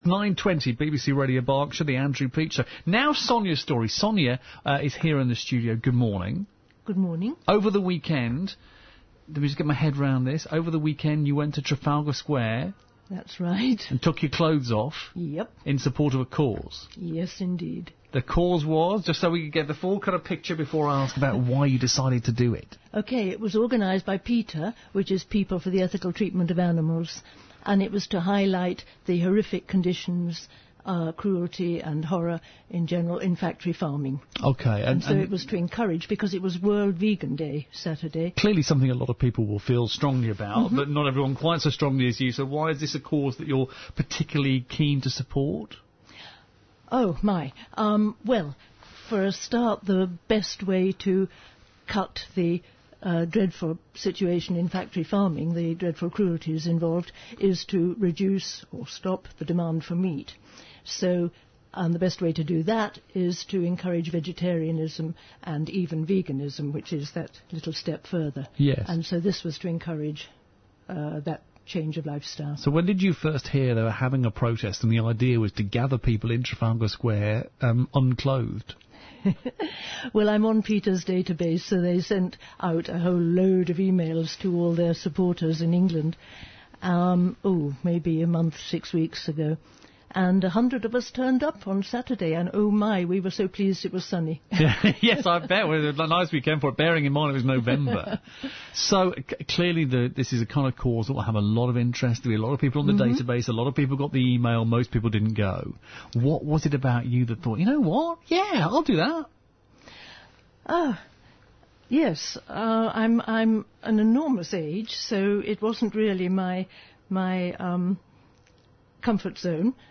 live on-air) to express her feelings about the occasion and the cause it represents.